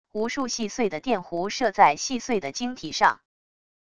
无数细碎的电弧射在细碎的晶体上wav音频